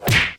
sounds_melee_hit_05.ogg